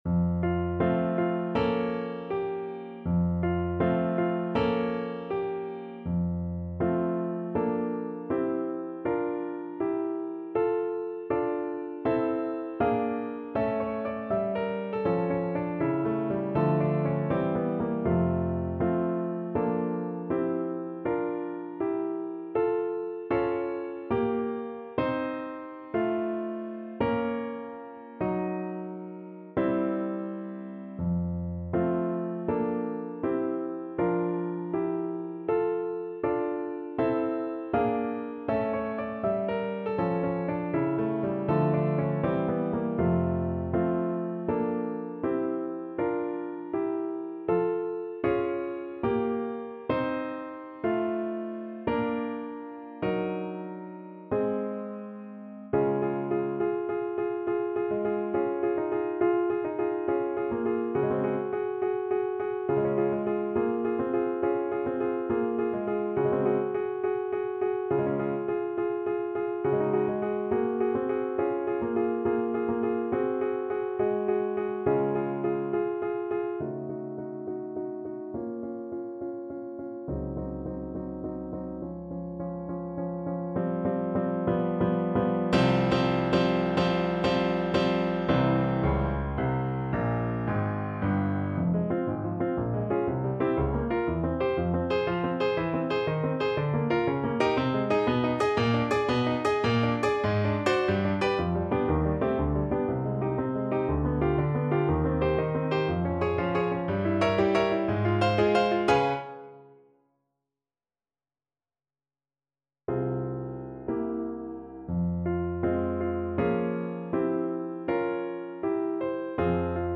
Moderato =80
2/4 (View more 2/4 Music)
Classical (View more Classical Voice Music)